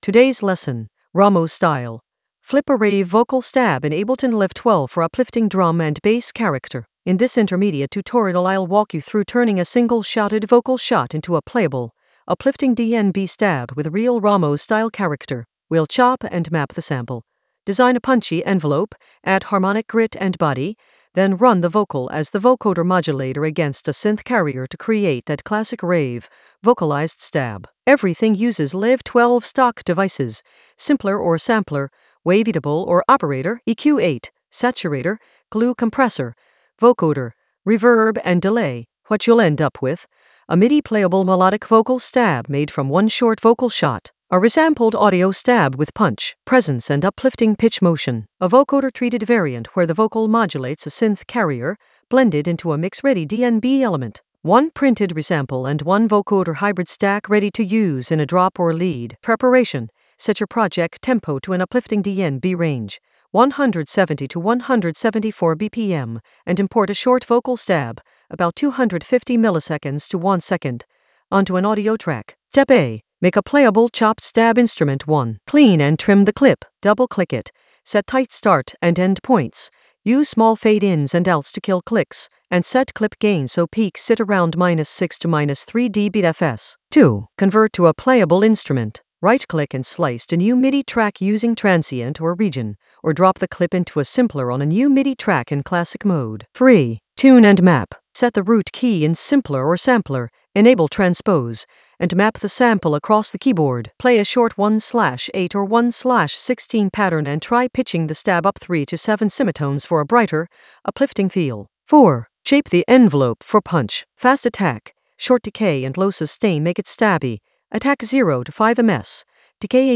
An AI-generated intermediate Ableton lesson focused on Ramos style: flip a rave vocal stab in Ableton Live 12 for uplifting drum and bass character in the Vocals area of drum and bass production.
Narrated lesson audio
The voice track includes the tutorial plus extra teacher commentary.